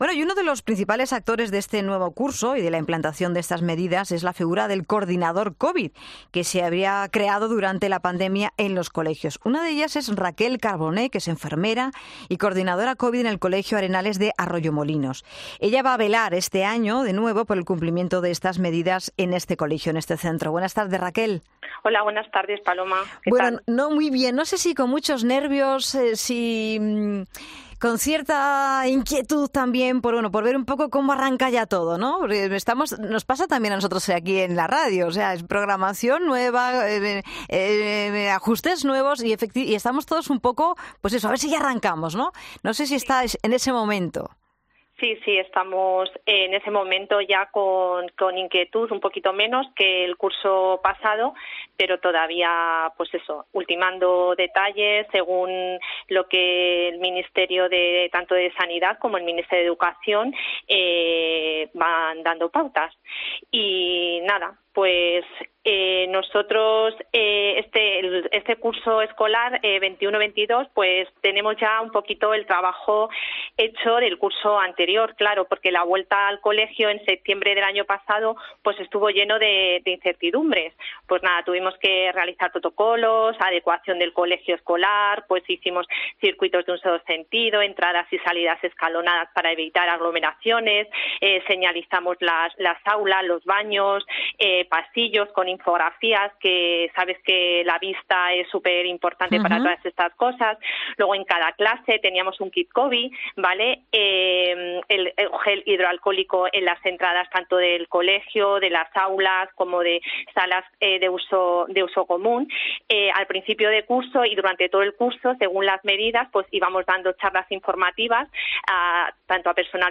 Así lo cuenta una coordinadora covid